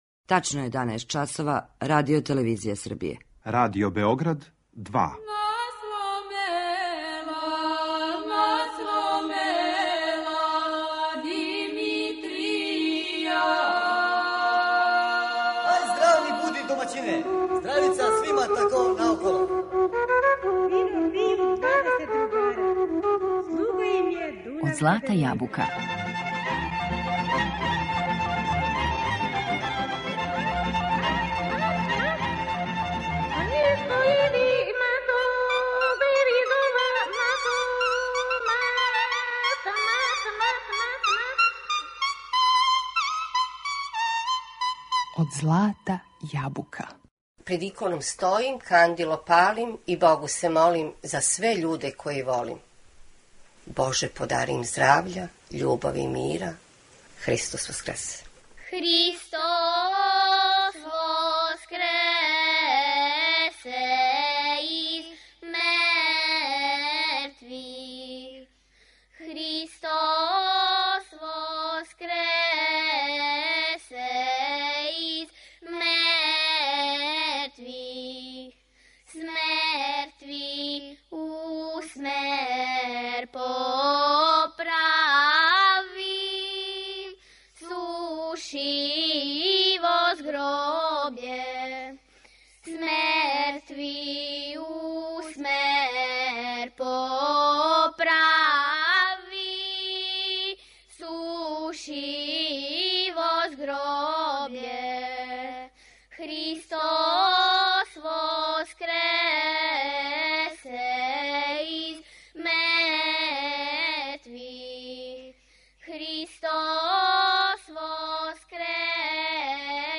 Слушаћете песме које се изводе у оквиру пролећног обичајног циклуса.